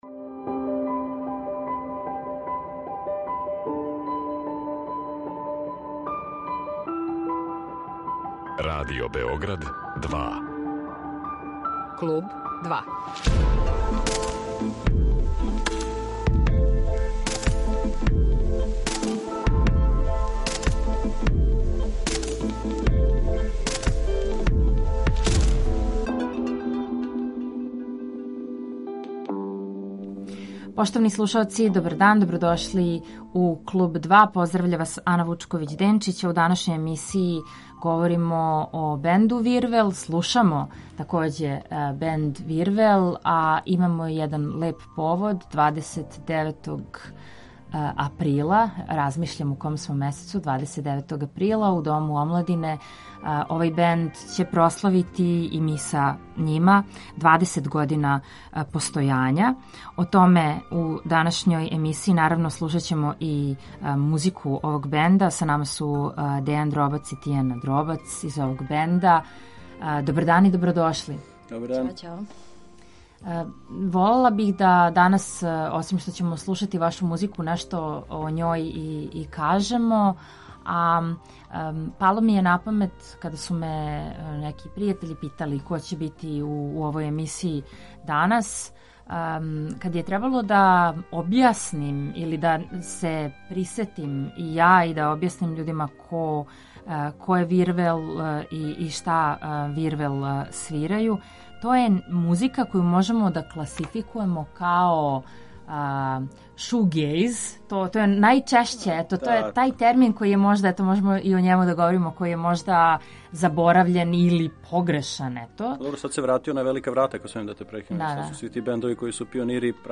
вокал, бас
гитара